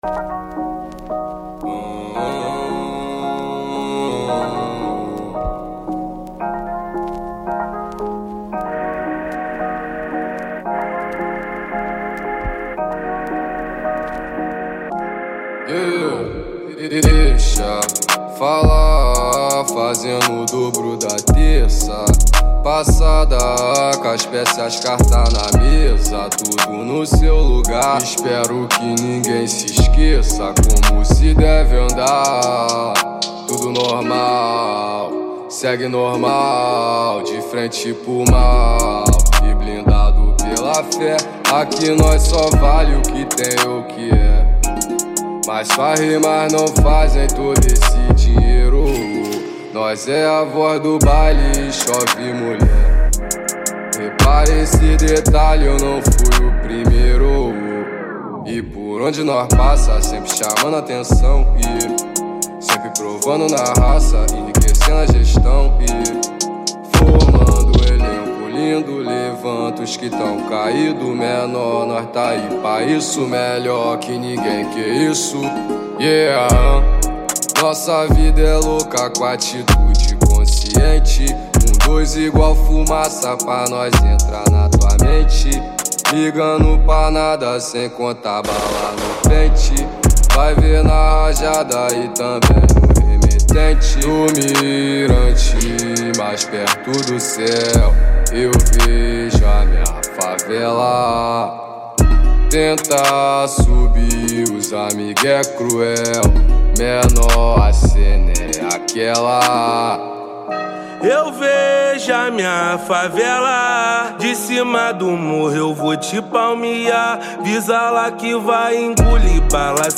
2025-03-19 01:44:38 Gênero: Trap Views